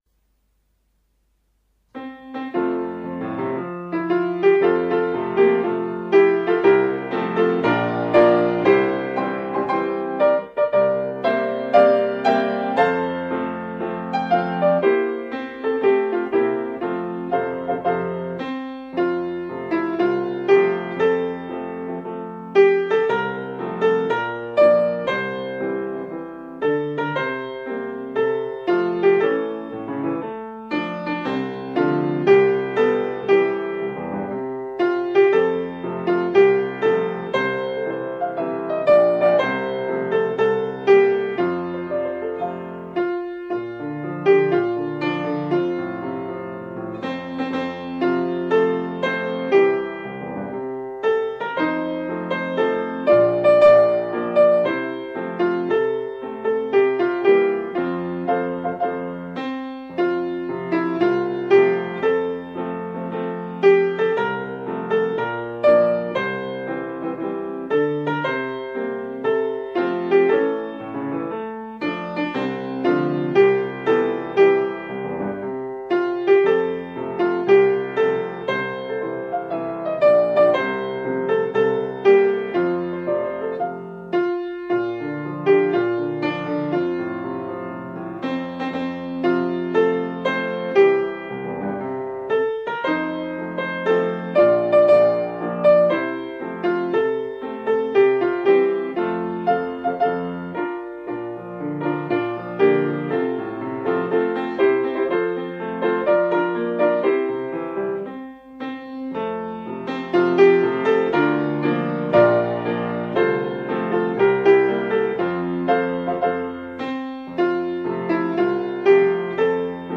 ピアノメロディーライン版
新たな収録は平成25年3月22日くにたち市民芸術小ホールのスタジオで行いました。